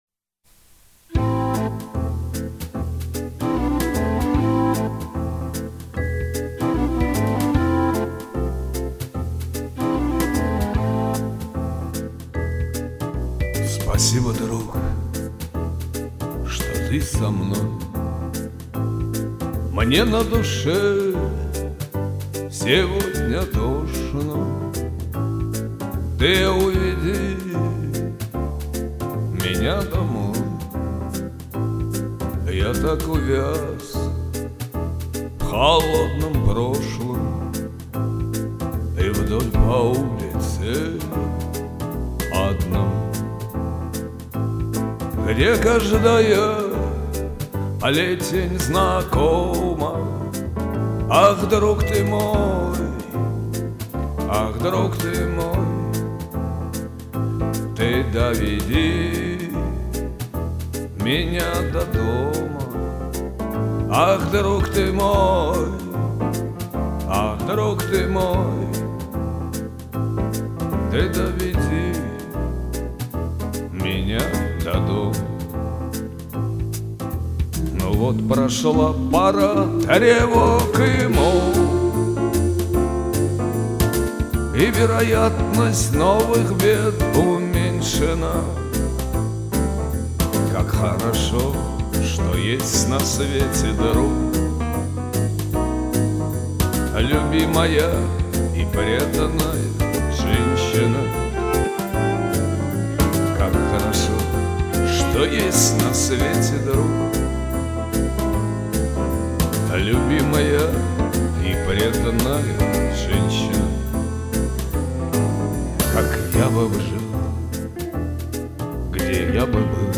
приятный тембр, но подача слабовата